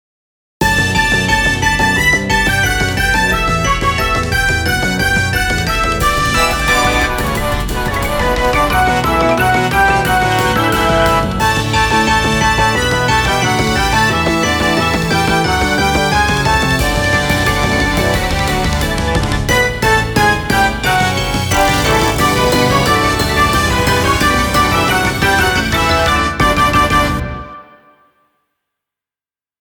どんちゃんわいわい、無法地帯。